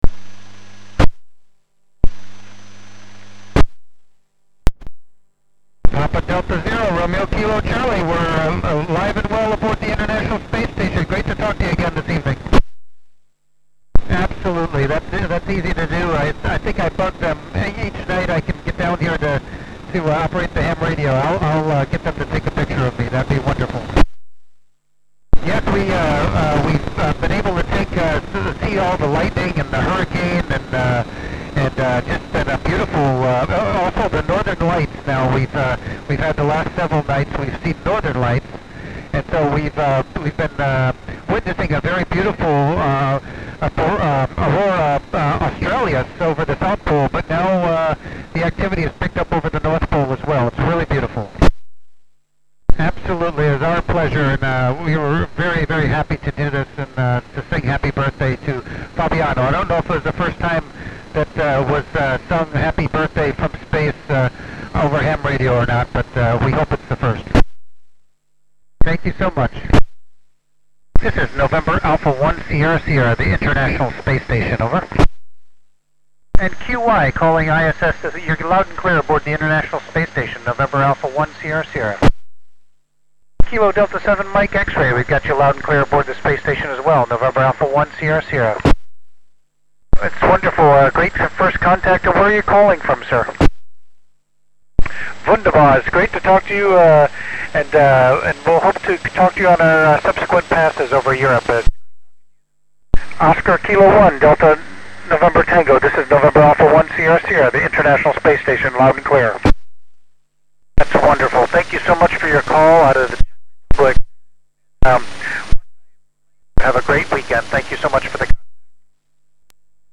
Heard in The Netherlands (Europe).